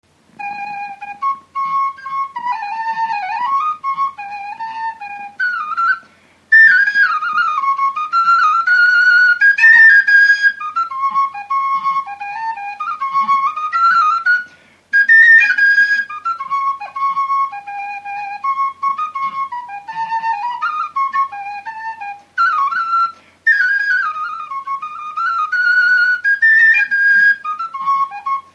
Dallampélda: Hangszeres felvétel
Hangszeres felvétel Felföld - Heves vm. - Bükkszék Előadó
furulya Gyűjtő